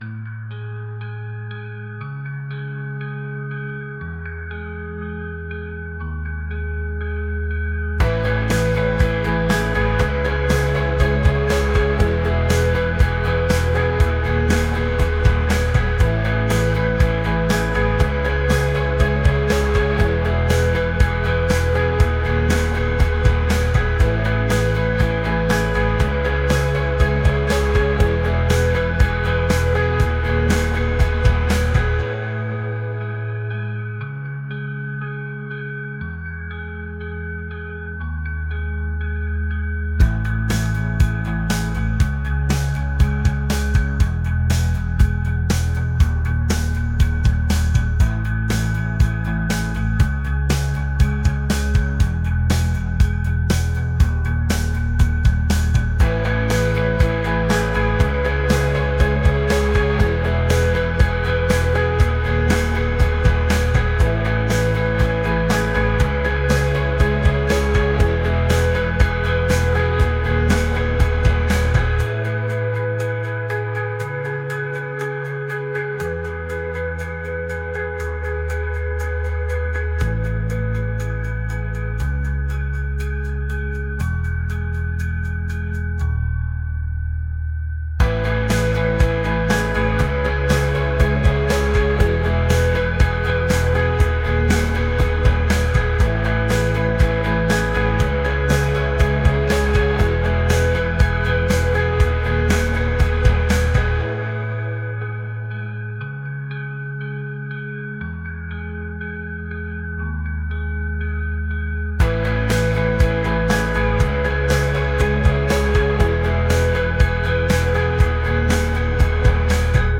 indie | pop | ambient